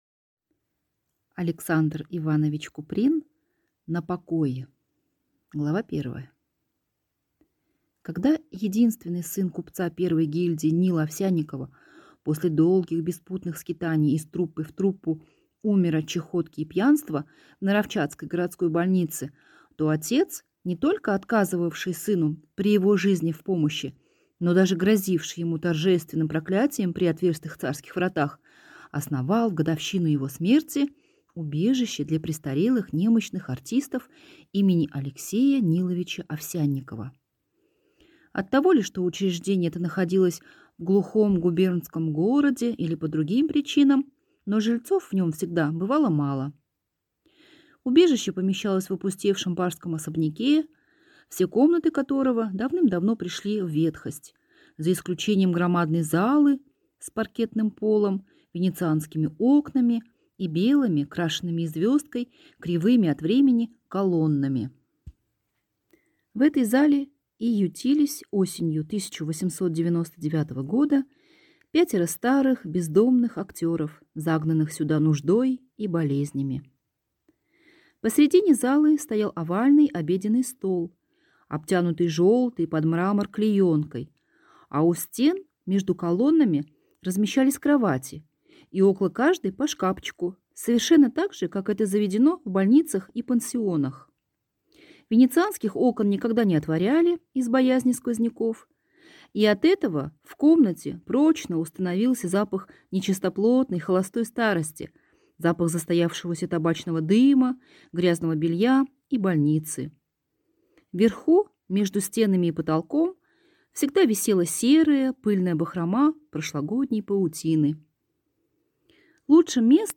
Аудиокнига На покое | Библиотека аудиокниг